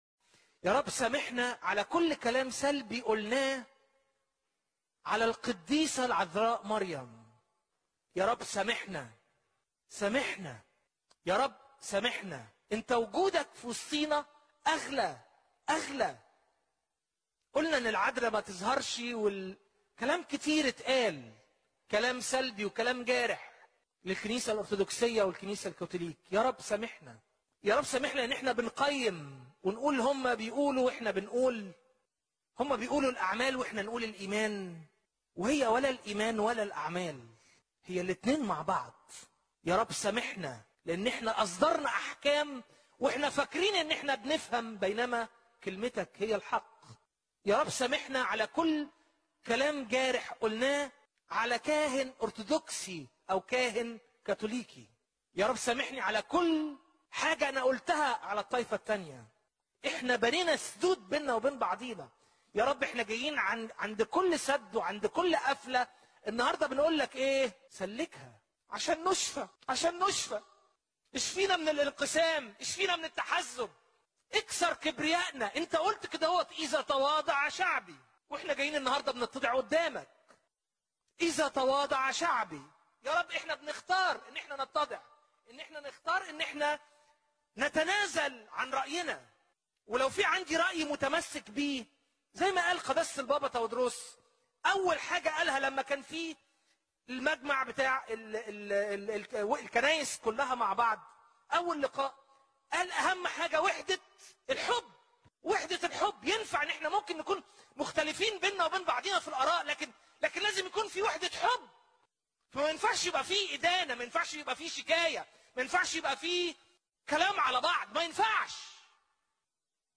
أحد القساوسة الإنجيليين في مصر، يتمنى وحدة الكنائس، ويقول: يا رب سامحنا، ع كل كلام قلتو بحق الكنائس الأخرى